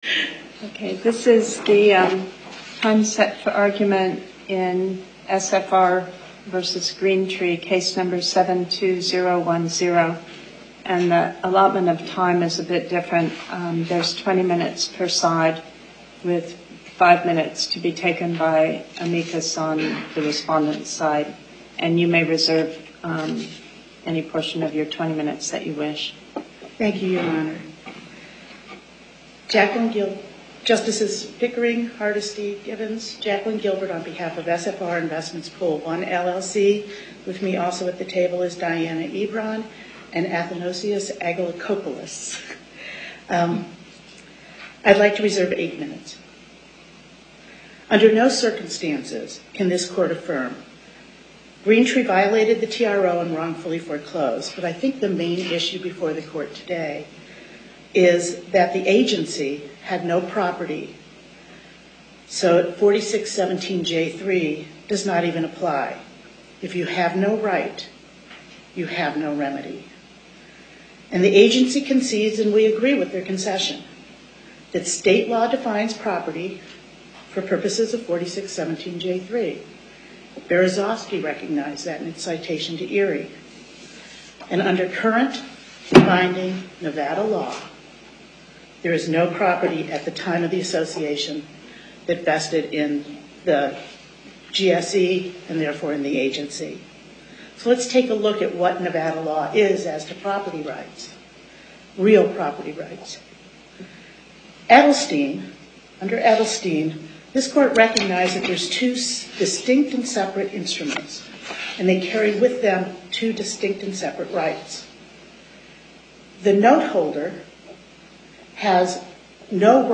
Location: Las Vegas Before the Northern Nevada Panel, Justice Pickering Presiding